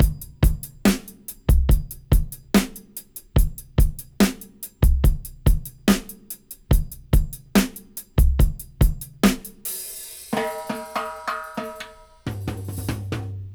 70-DRY-01.wav